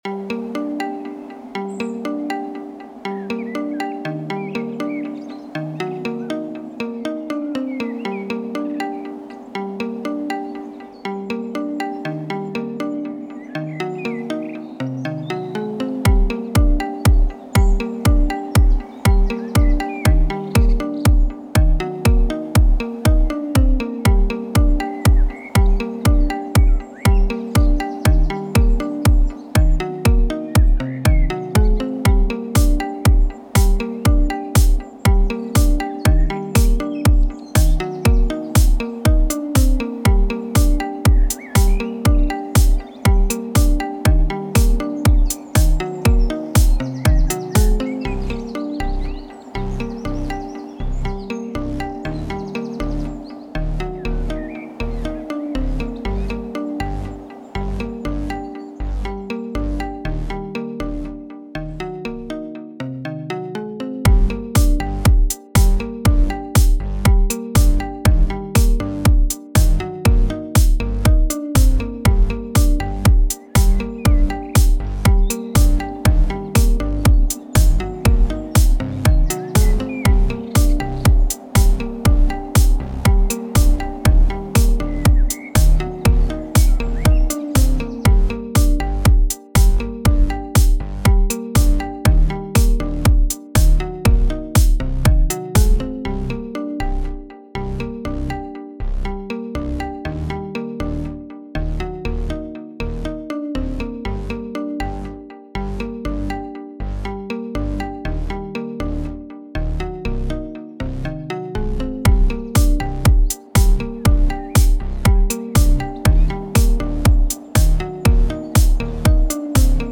synthwave electropop
electronicmusic